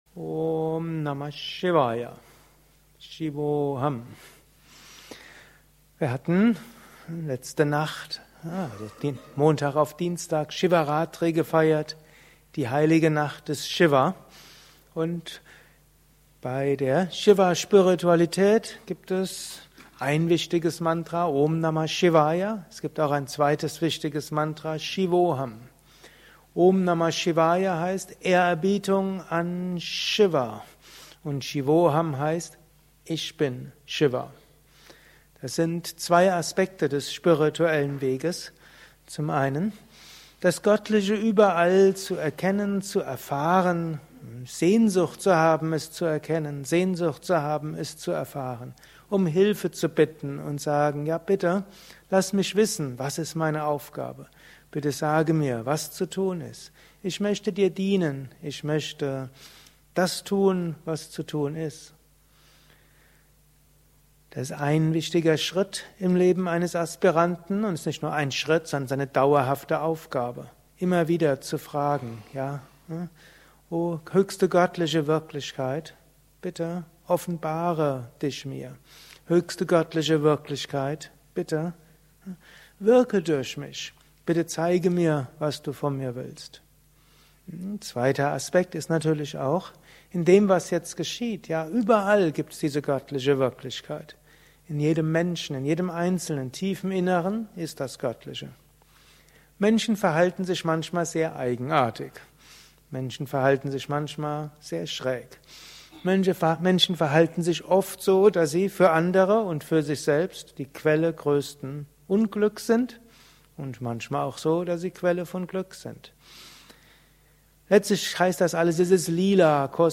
Anschluss nach einer Meditation im Haus Yoga Vidya Bad Meinberg.